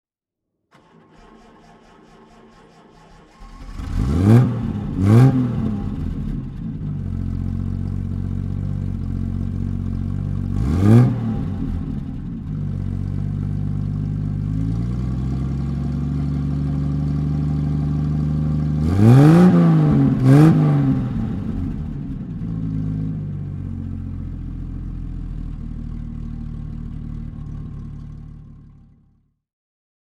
Triumph TR6 (1974) - Starten und Leerlauf
Triumph_TR6_1974.mp3